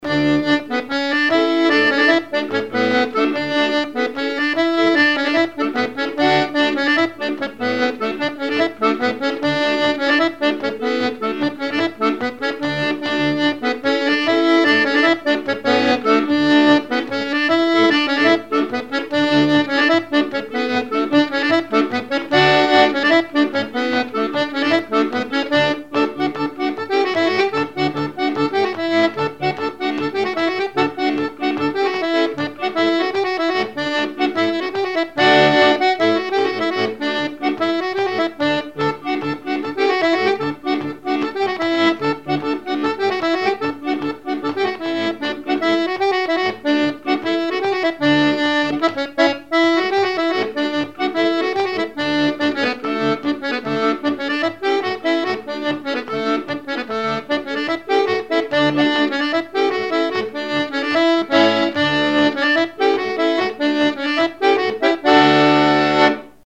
danse : branle : courante, maraîchine
instrumentaux à l'accordéon diatonique
Pièce musicale inédite